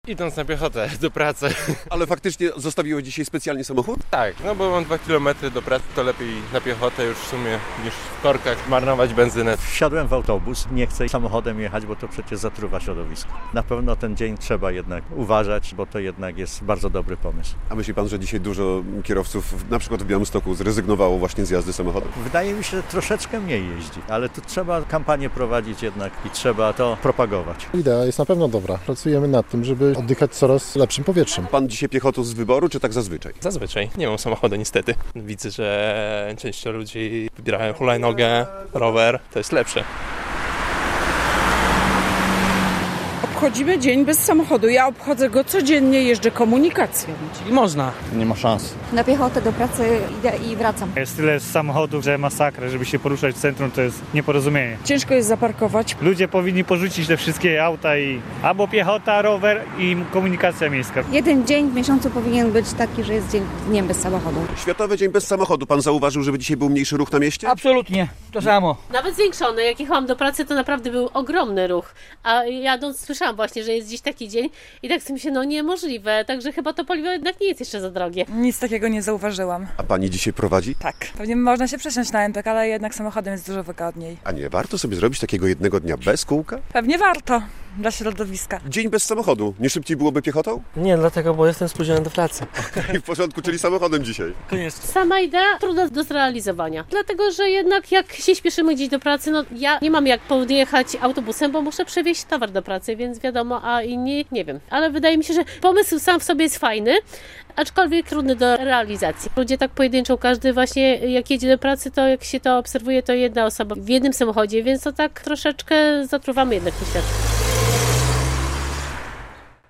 Dzień Bez Samochodu w Białymstoku - relacja